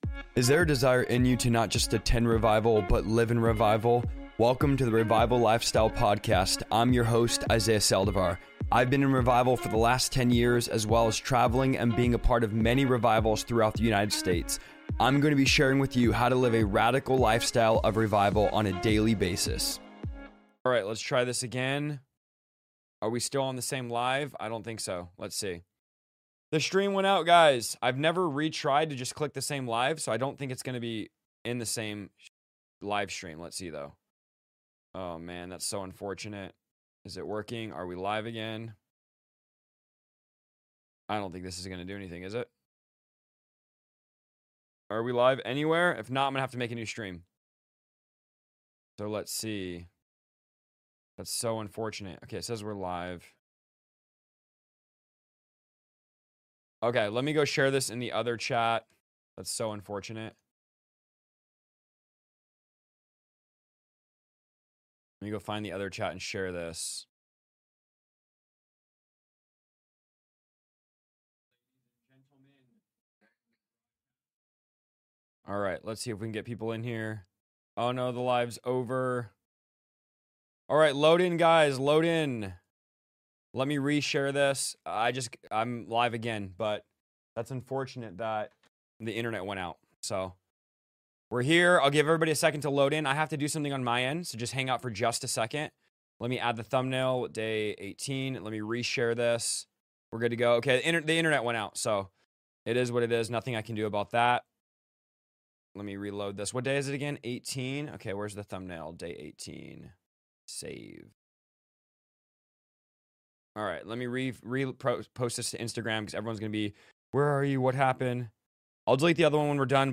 I am going to be LIVE everyday at 2 PM for 90 days straight reading through the entire Bible!